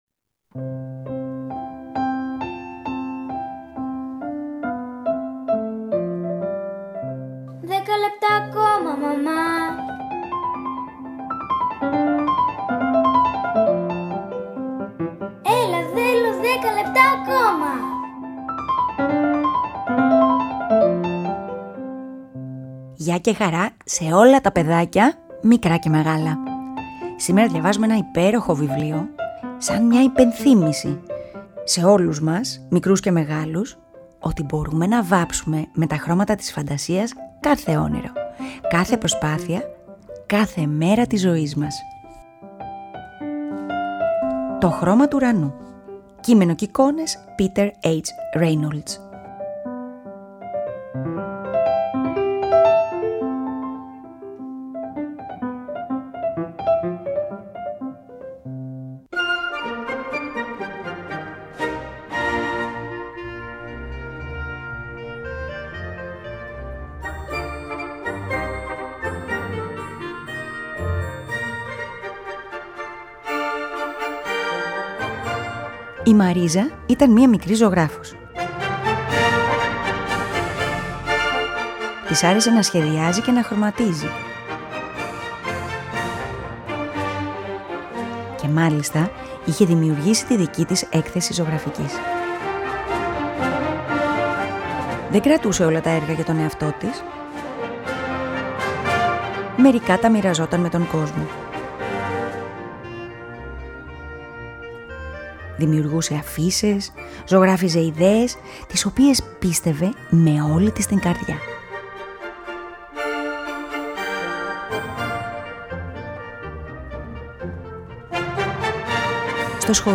Αφήγηση-Μουσικές επιλογές
ΠΑΡΑΜΥΘΙΑ